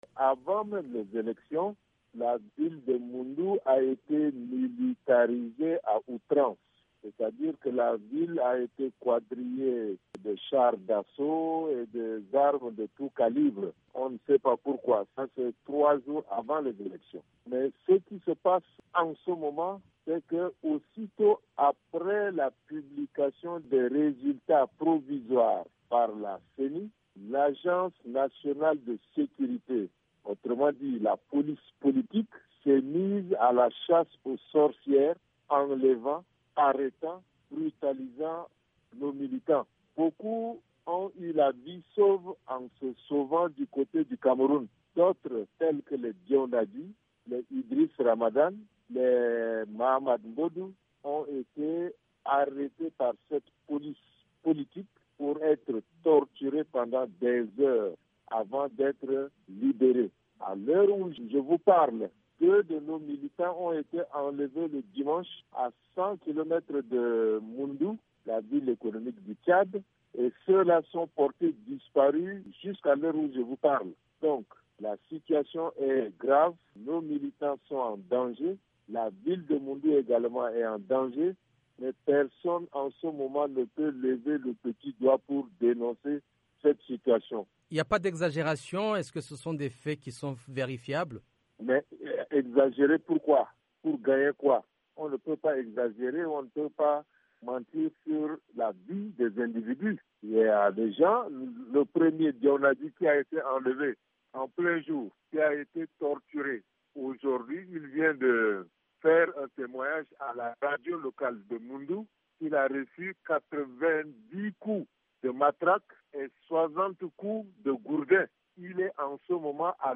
Laokein Kourayo Medar joint par